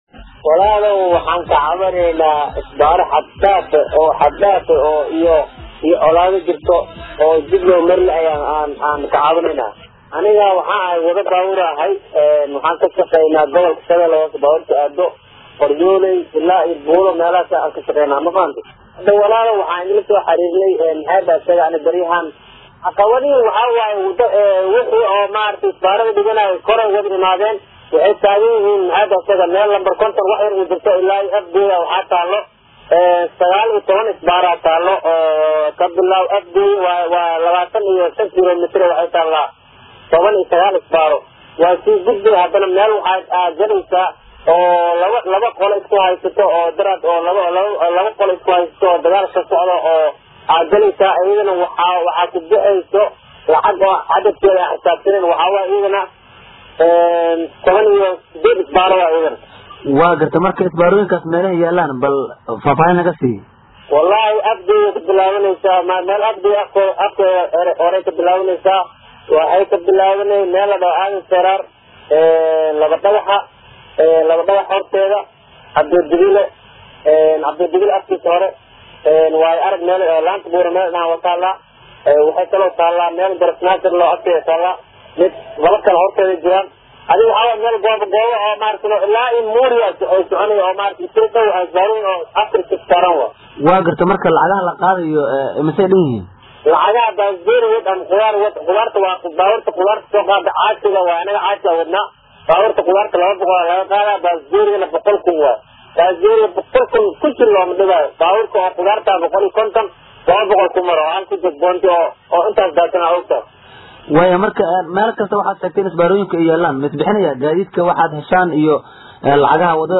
Darawalada qaar oo la hadlay barnaamijka Cabashada dadweynaha ayaa U  sheegay Warbaahintain isbaarooyinku ay noqdeen kuwo la isaga daysado oo ninkii qori wataaba uu meel dhigto kuwaas oo badankood lebisan sida darawaladu ay sheegeen dharka ciidanka.